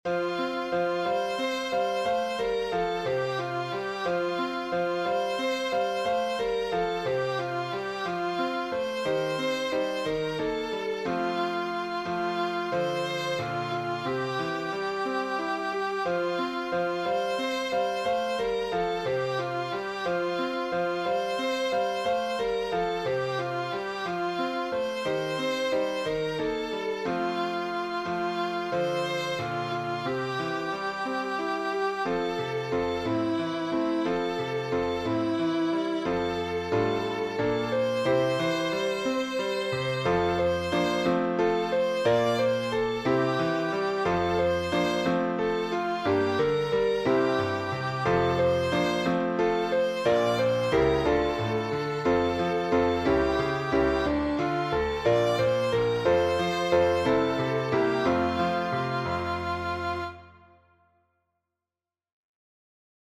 uplifting, spirited hymn